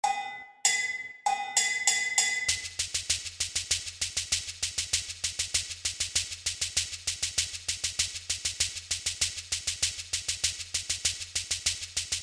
Chékeres
Beaded gourd instrument originally played in sets of three or more and divided into parts called: kachimbo, segundo e caja.